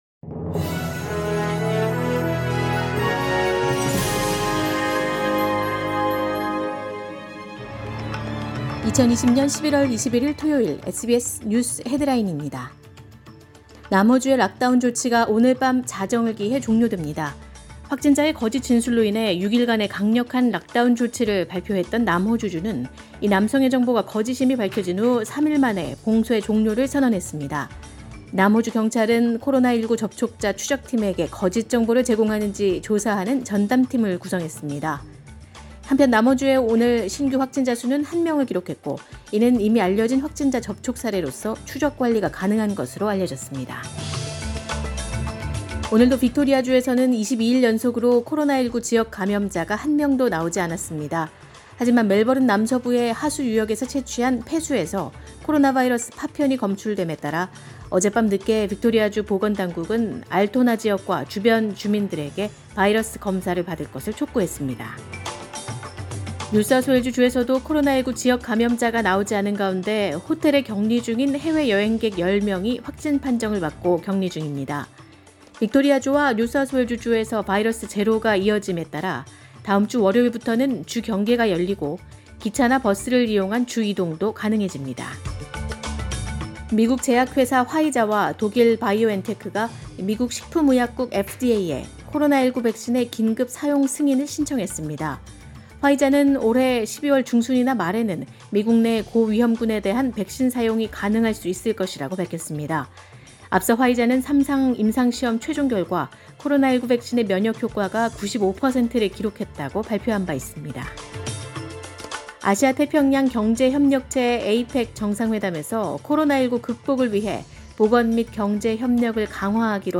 2020년 11월 21일 토요일 오전의 SBS 뉴스 헤드라인입니다.